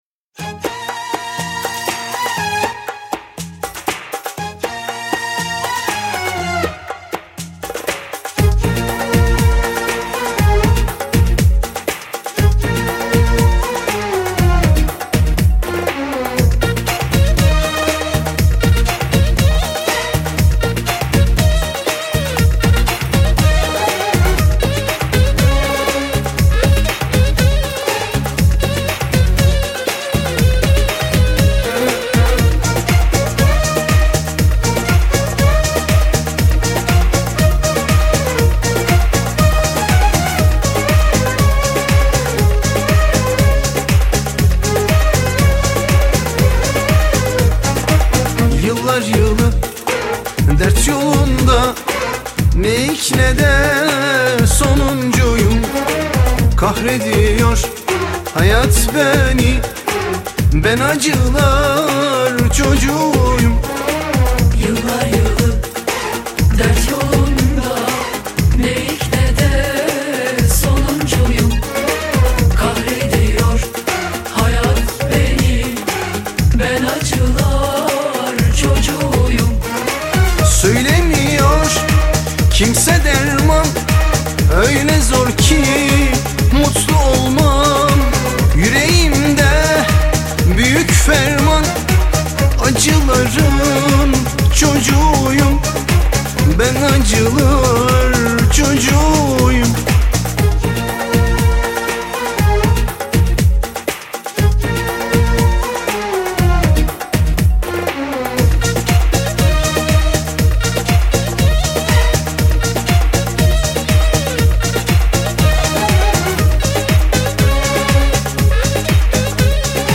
ژانر: پاپ ترکی